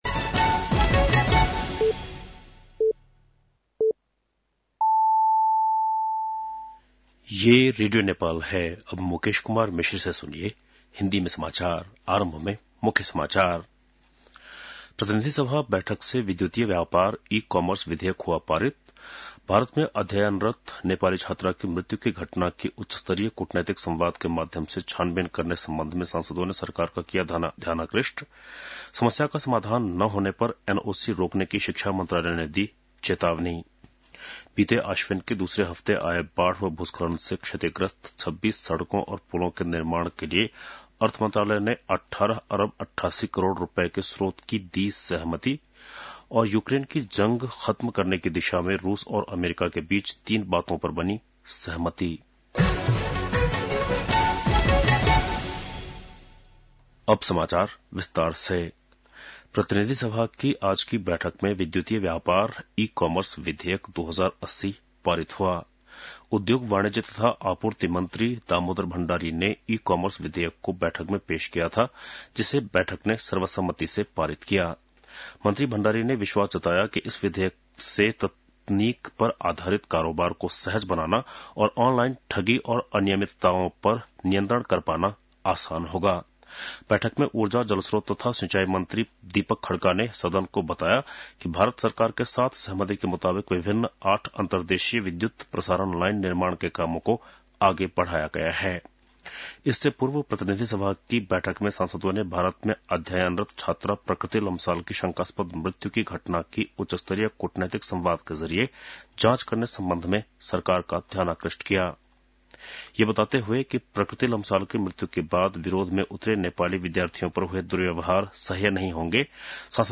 बेलुकी १० बजेको हिन्दी समाचार : ७ फागुन , २०८१